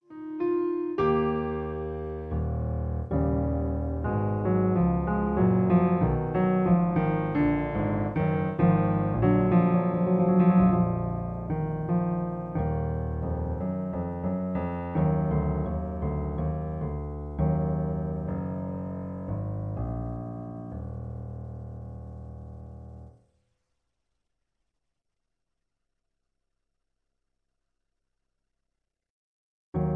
In E flat. Piano Accompaniment